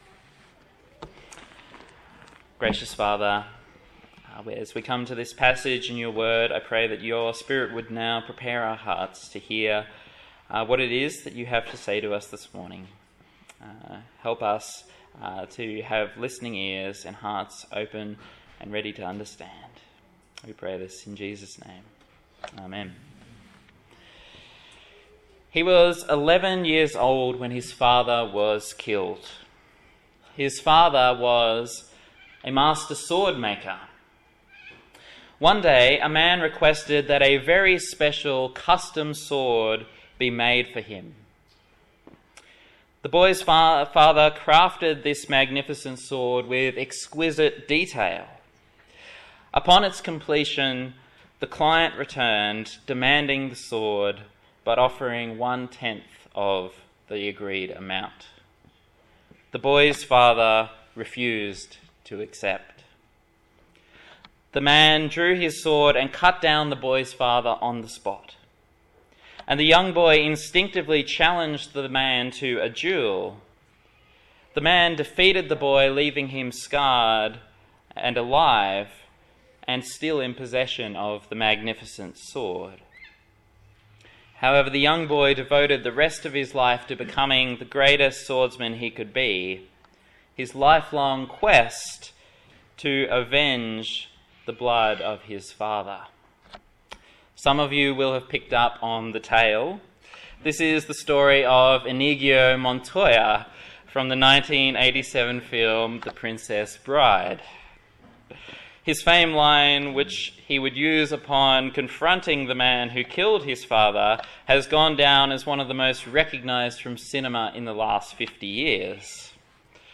Service Type: Sunday Morning A sermon in the Kingdom Come series on the Gospel of Matthew